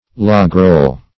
Logroll \Log"roll`\, v. i. & t.